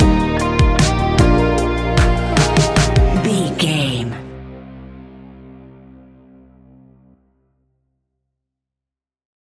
Ionian/Major
C♭
ambient
electronic
chill out
downtempo